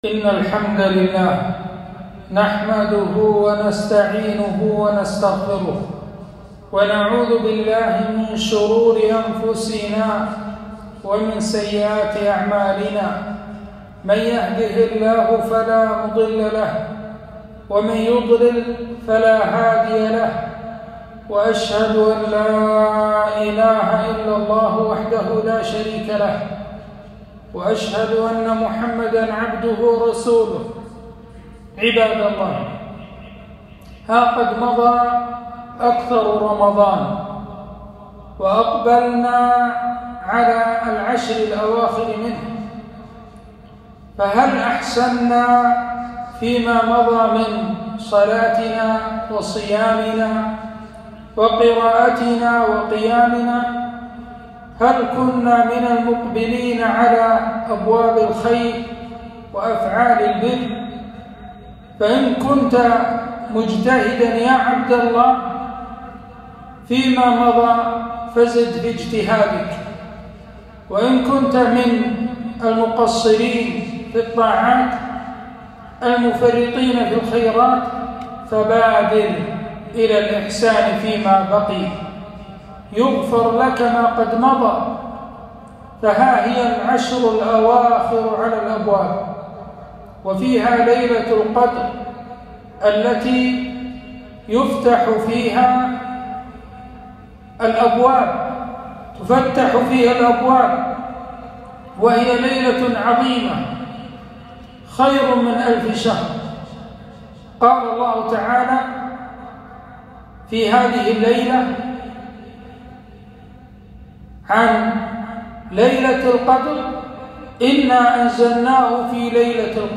خطبة - فضل العشر الأواخر وليلة القدر